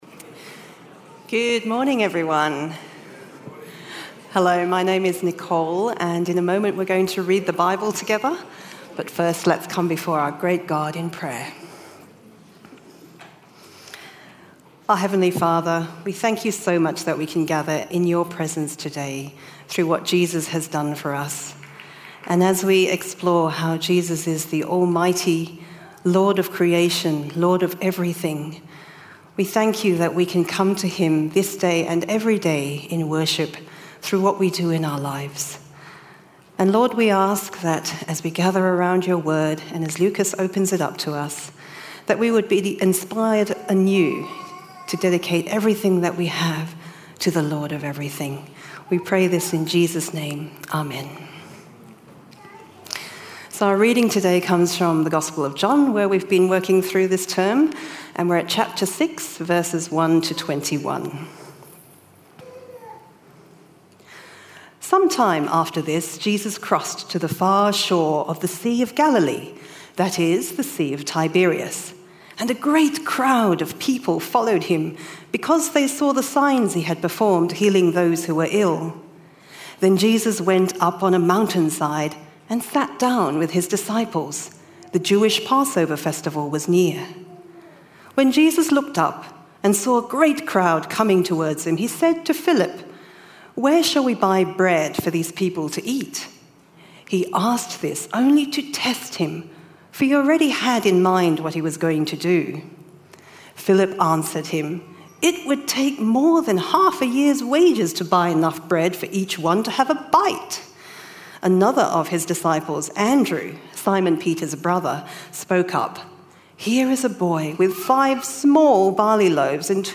Bible Reading (John 6:1-21) + Talk